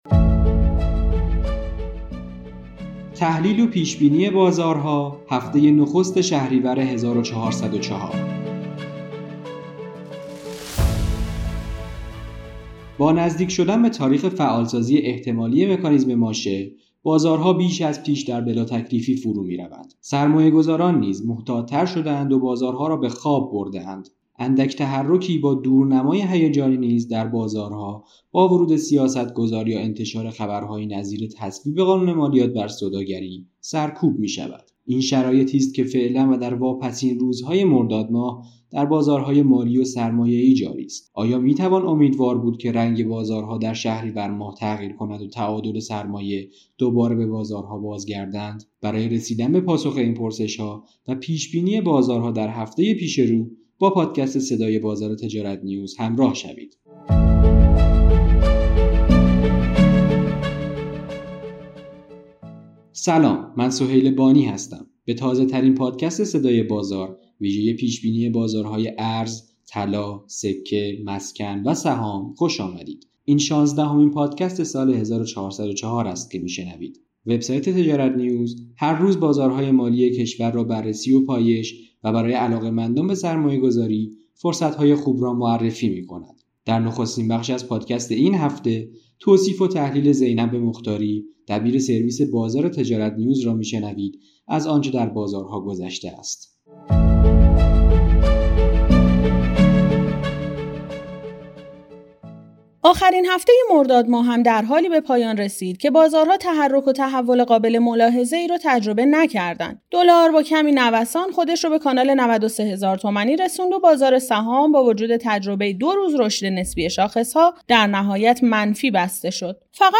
به گزارش تجارت نیوز، به تازه‌ترین پادکست صدای بازار ویژه پیش بینی بازارهای ارز، طلا، سکه، مسکن و سهام خوش آمدید.